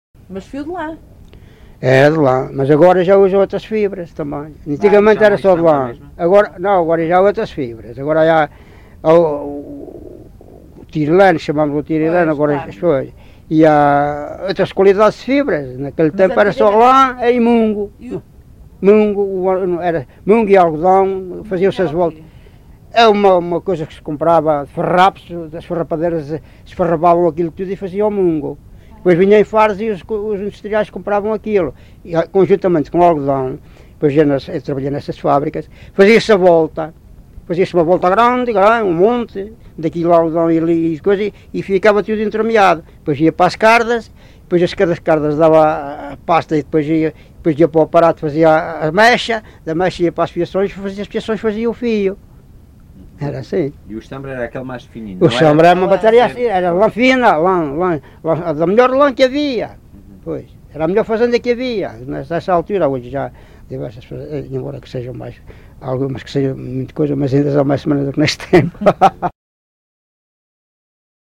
LocalidadeUnhais da Serra (Covilhã, Castelo Branco)